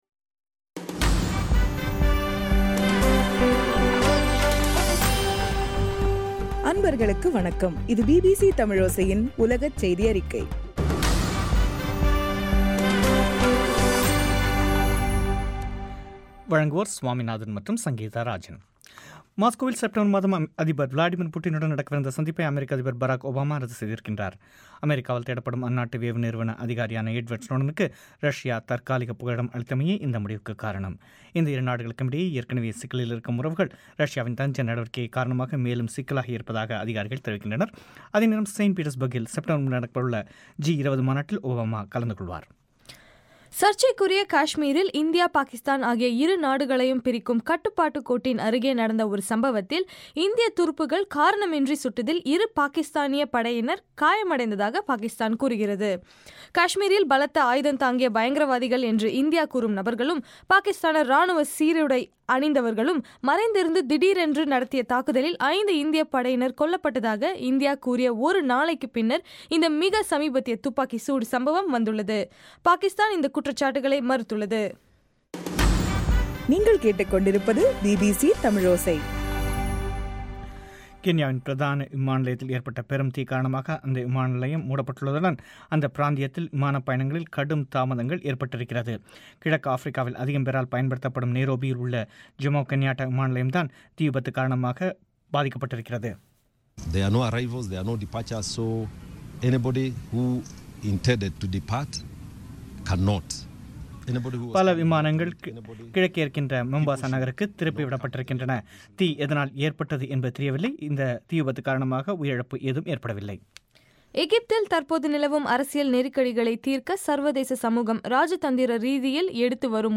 ஆகஸ்டு 7 பிபிசி உலகச்செய்தி அறிக்கை